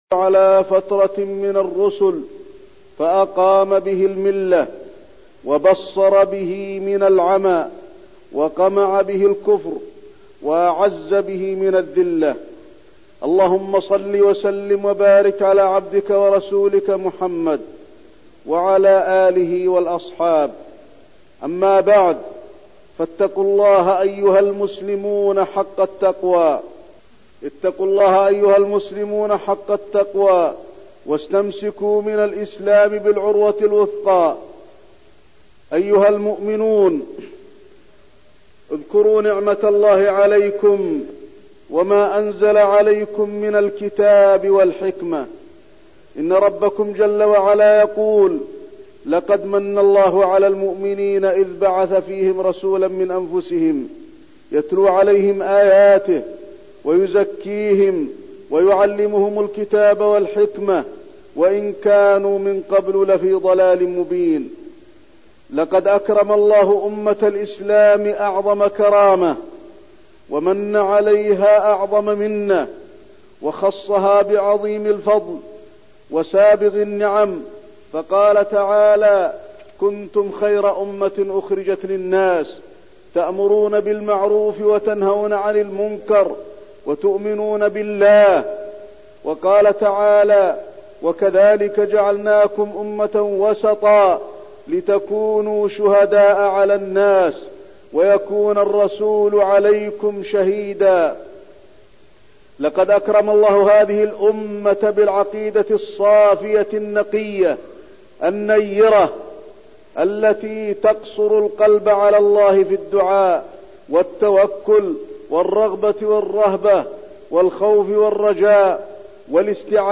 تاريخ النشر ٢٧ رجب ١٤١٢ هـ المكان: المسجد النبوي الشيخ: فضيلة الشيخ د. علي بن عبدالرحمن الحذيفي فضيلة الشيخ د. علي بن عبدالرحمن الحذيفي الحث على العلم والعبادة